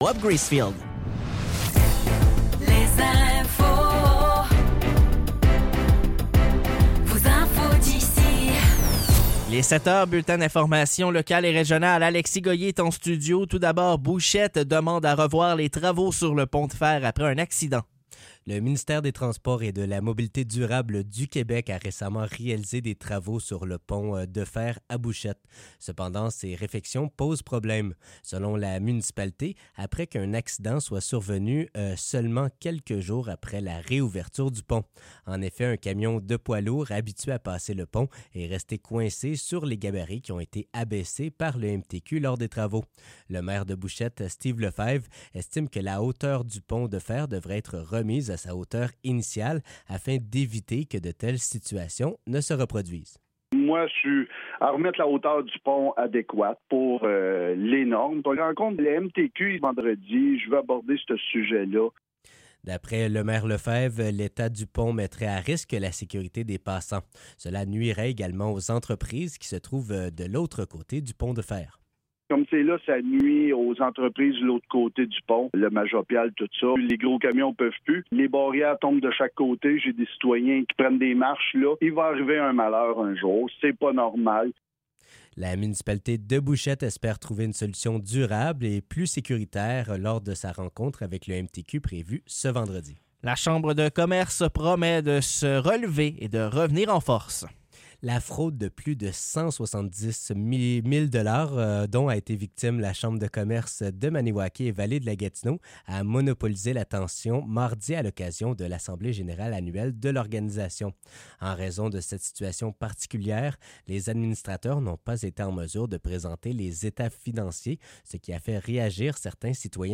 Nouvelles locales - 21 novembre 2024 - 7 h